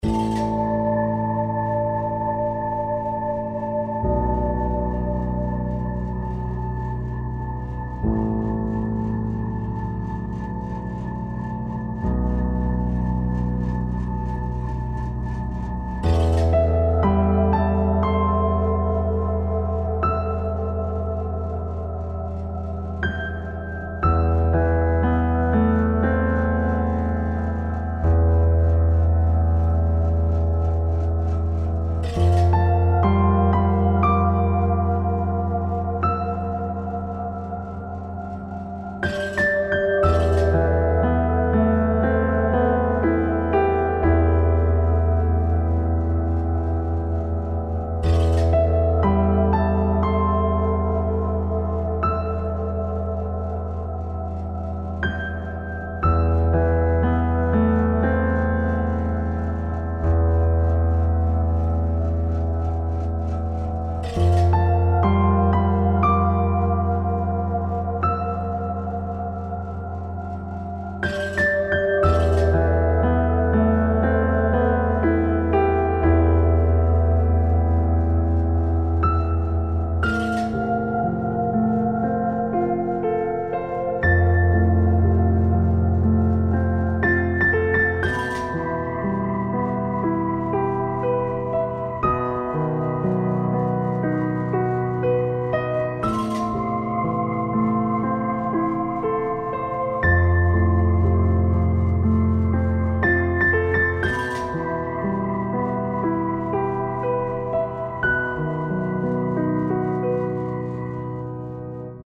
ファンタジー系フリーBGM｜ゲーム・動画・TRPGなどに！